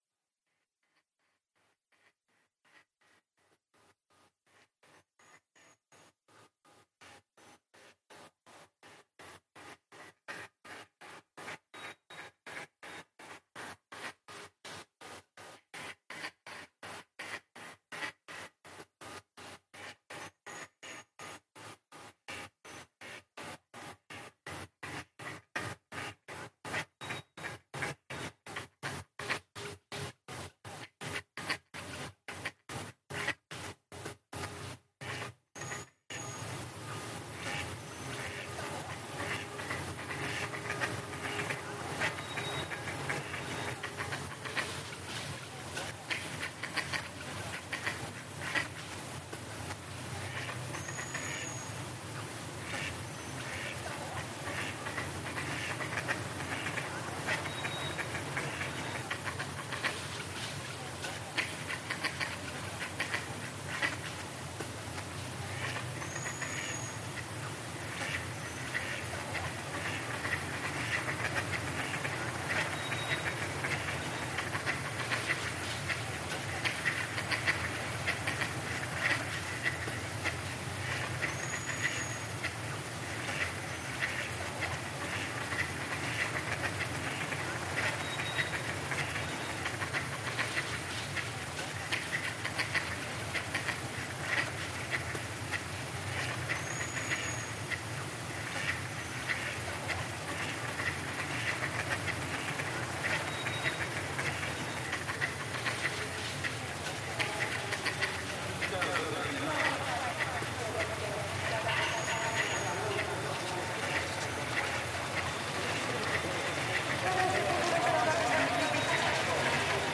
Manchester-based sound artists